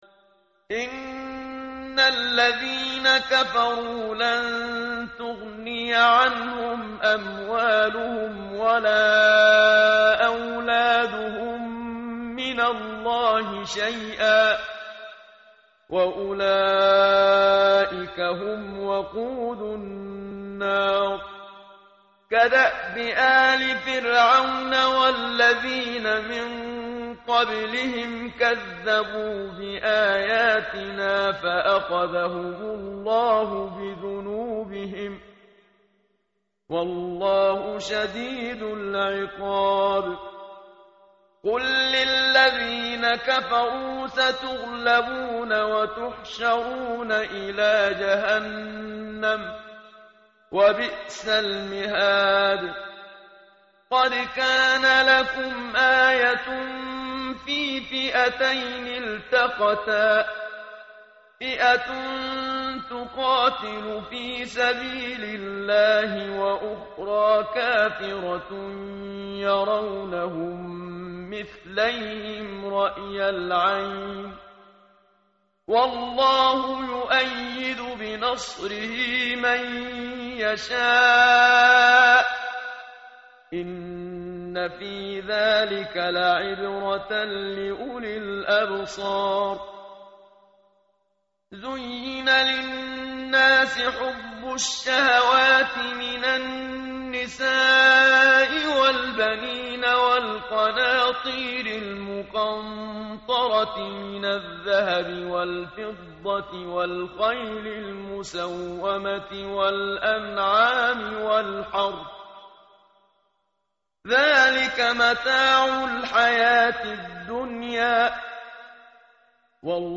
متن قرآن همراه باتلاوت قرآن و ترجمه
tartil_menshavi_page_051.mp3